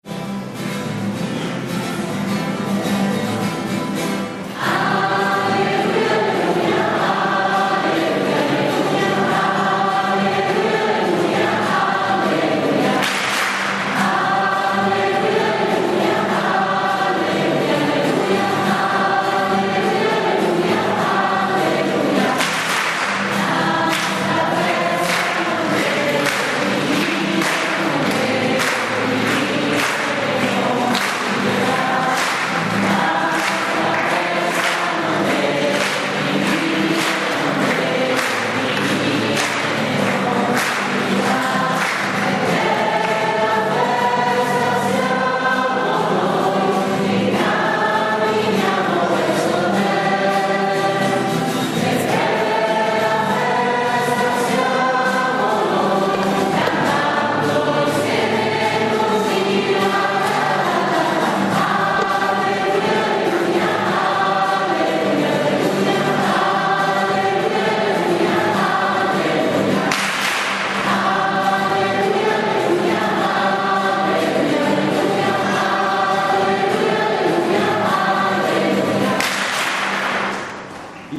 Coro - Oratorio San Carlo Arona
Siamo, a pieno regime, un gruppo di 21 elementi, tra coristi e suonatori.